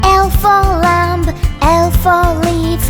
Download L for Lamb sound effect for free.